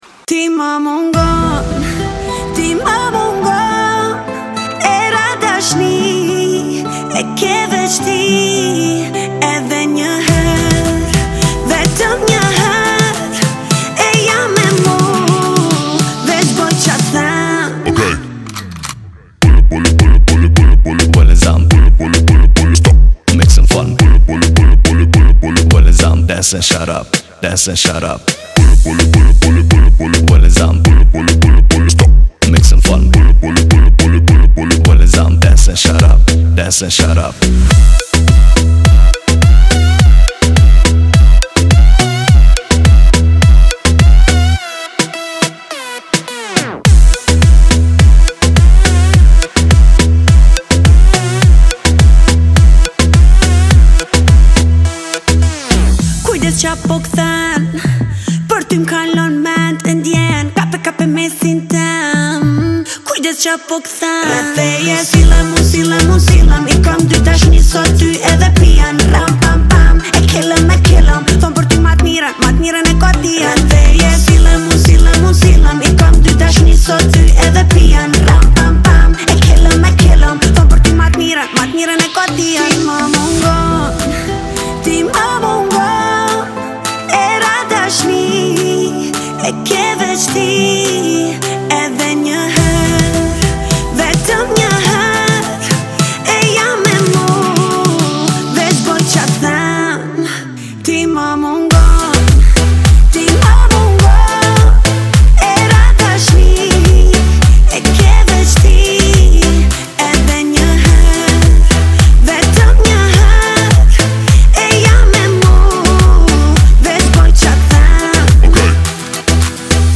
это зажигательная песня в жанре поп